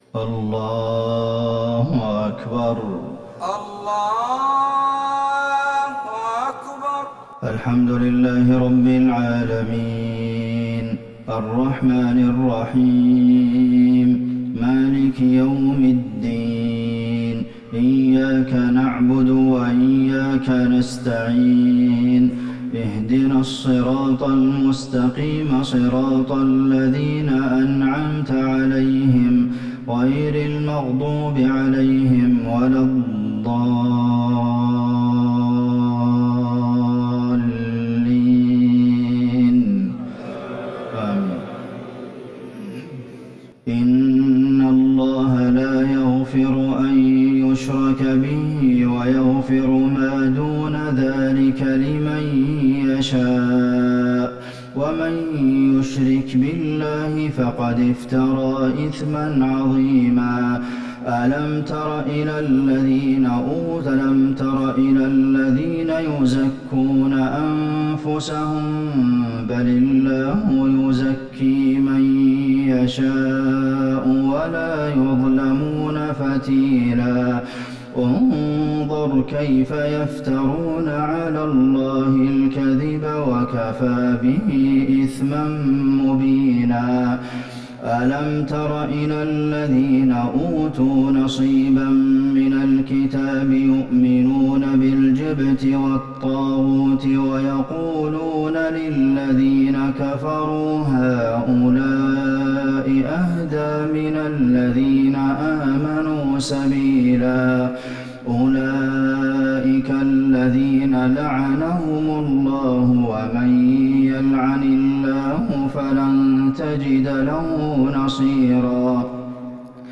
صلاة العشاء للشيخ عبدالمحسن القاسم 27 ربيع الآخر 1441 هـ